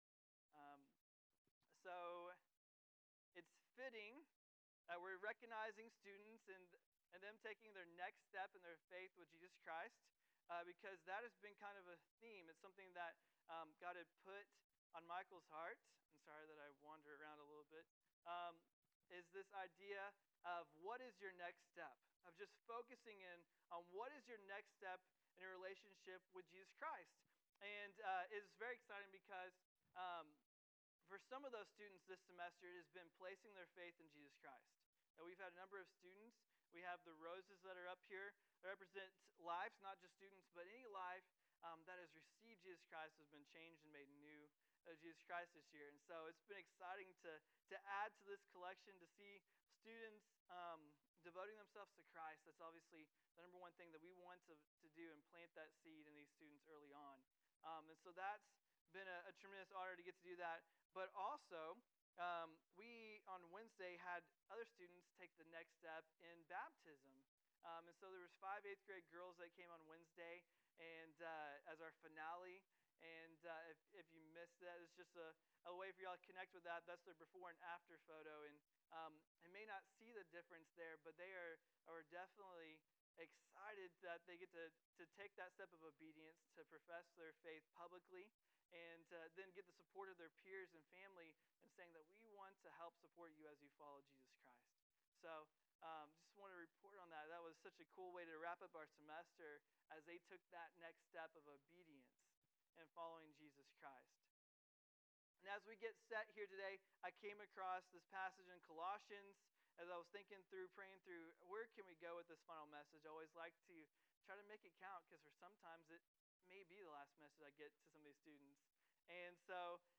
Graduation Sermon - 2018.mp3